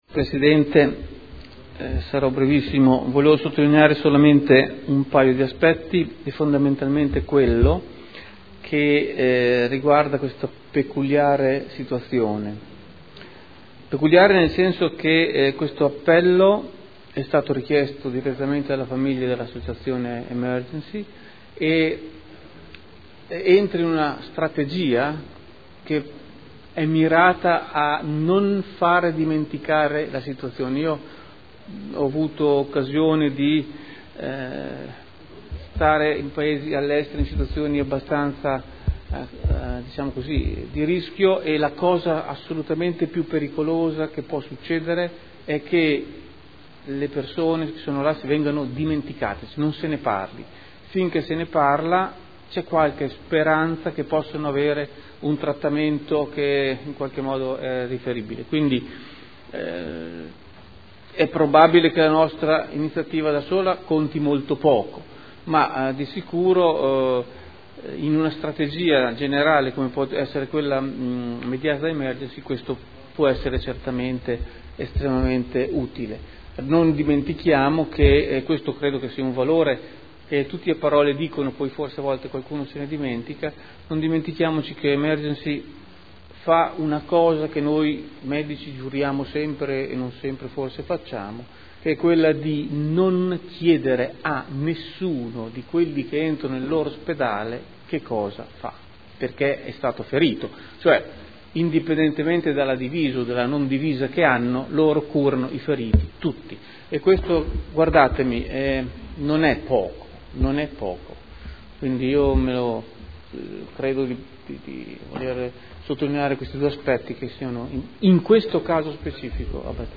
Audio Consiglio Comunale / Consigliatura 2009-14 / Archivio 2011 / settembre / Seduta del 5 settembre 2011